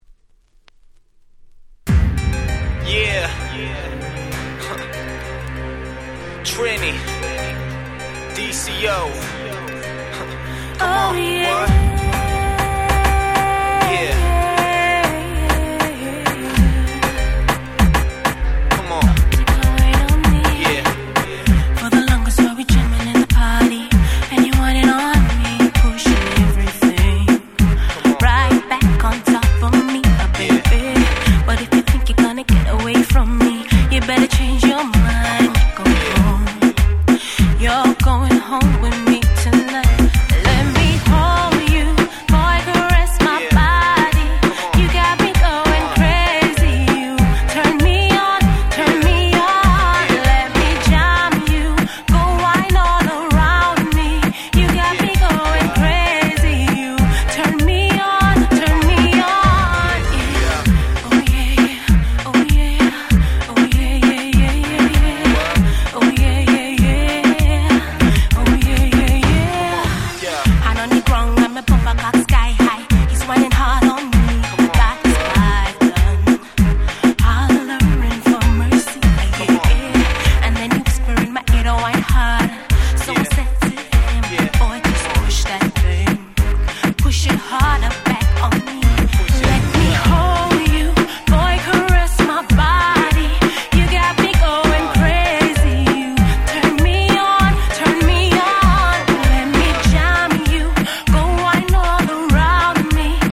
の女性カバー！！
超キャッチーだしレゲトンバージョンも入っちゃってるしでこんなの説明不要でしょう！！